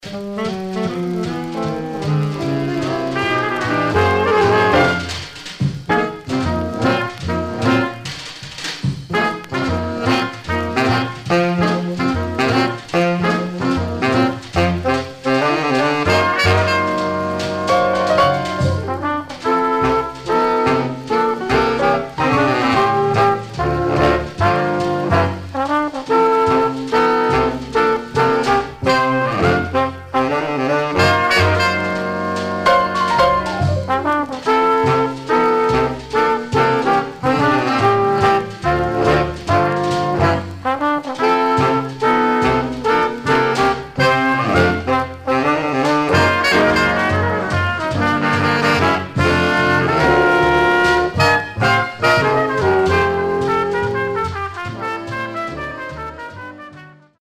Mono
Jazz